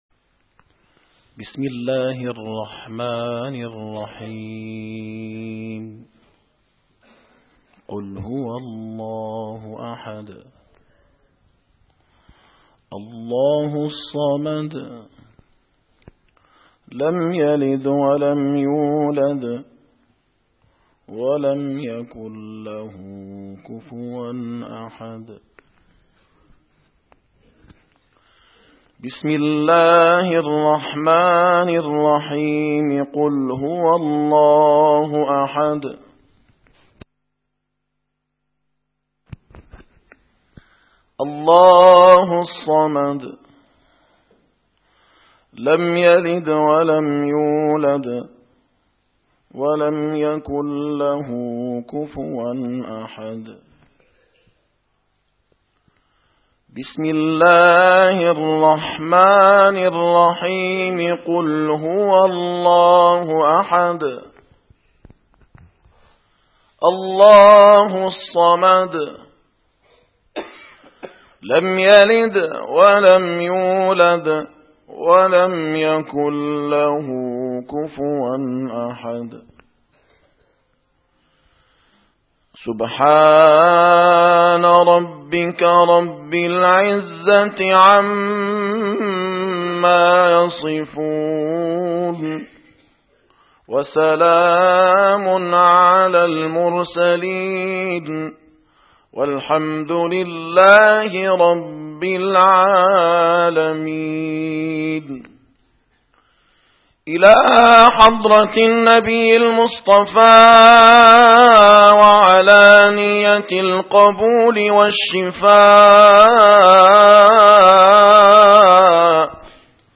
- الخطب - فرحة المولد ترجمة للحب وهو أصل الاتباع